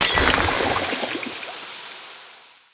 barkeep_bottle_hit_01.wav